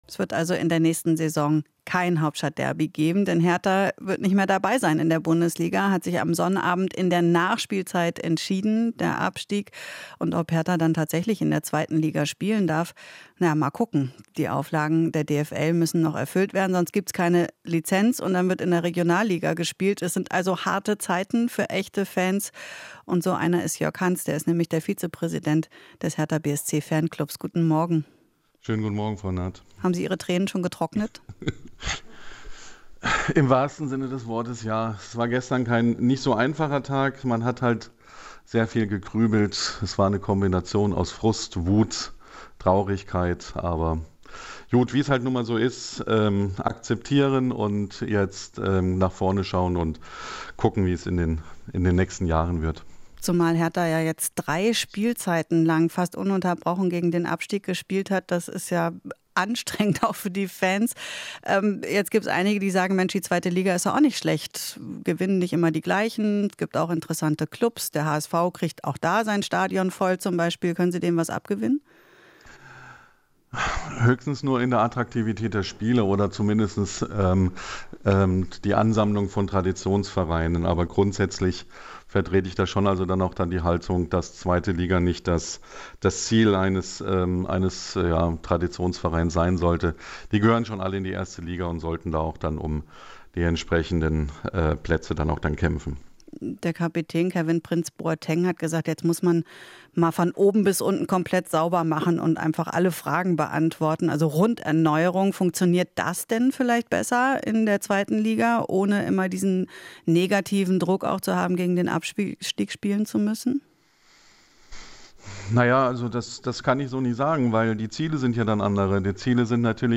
Interview - Hertha-Fanclub: Wir machen das Stadion auch in Liga 2 voll